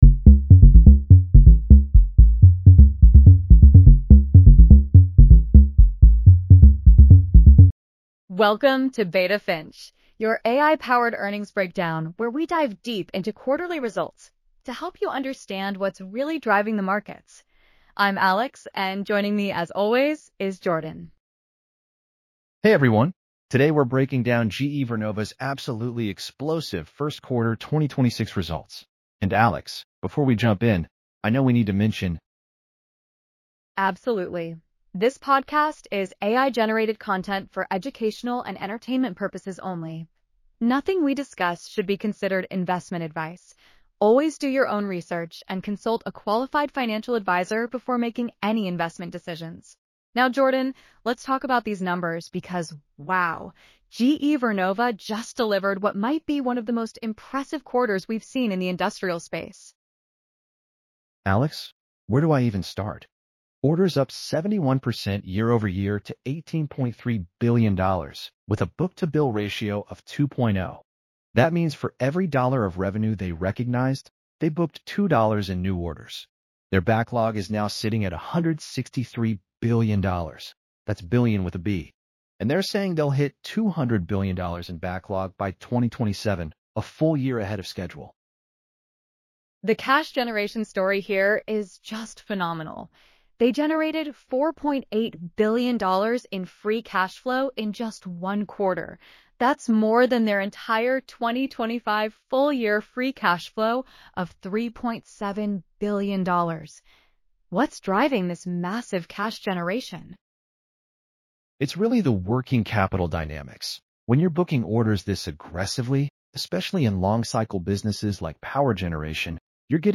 This is AI-generated content for educational purposes only.